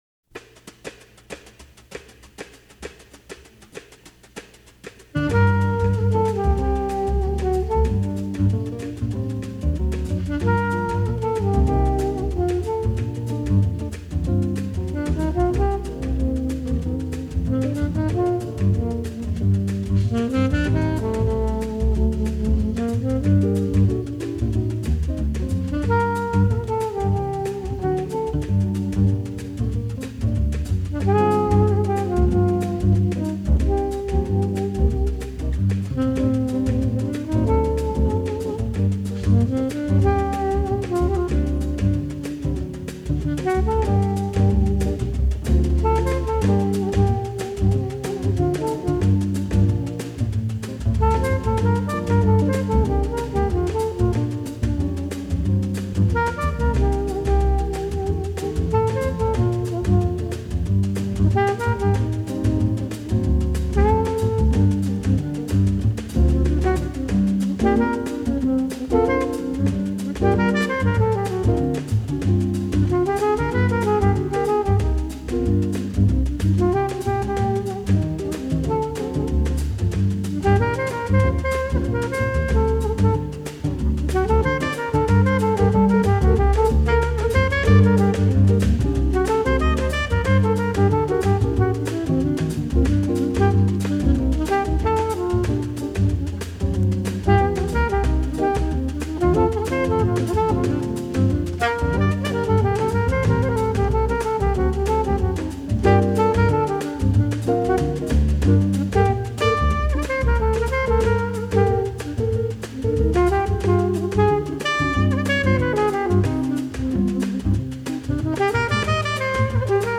боссанова
На альт-саксофоне